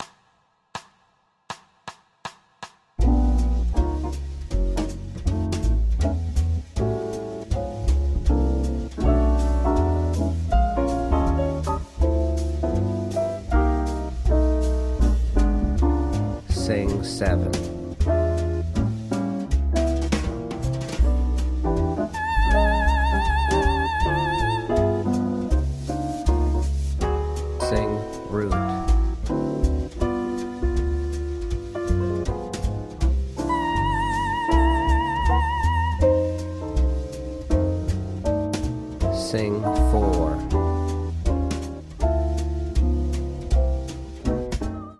• Bb Rhythm Jazz Singing Soprano